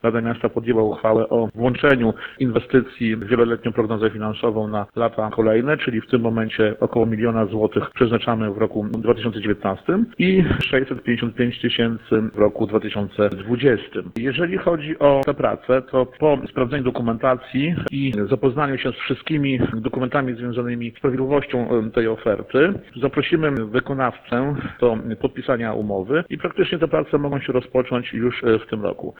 O innych atrakcjach mówi burmistrz.